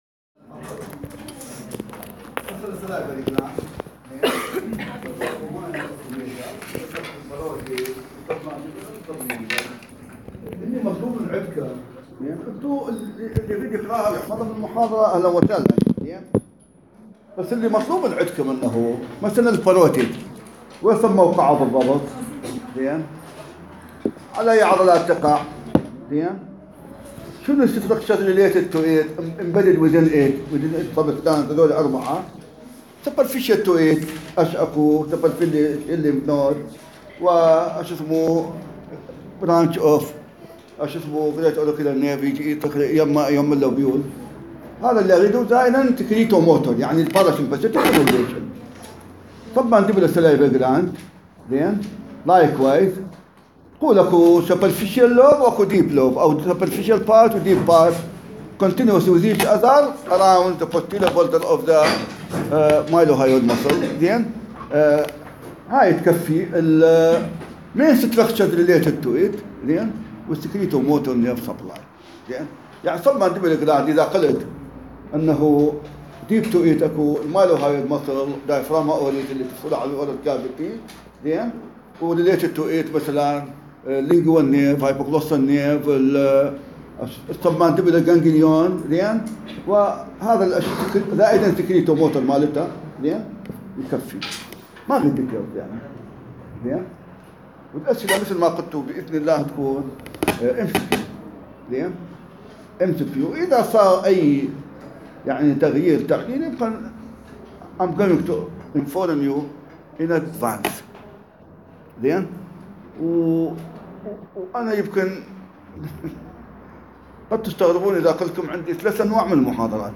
التشريح > محاضرة رقم 2 بتاريخ 2015-11-22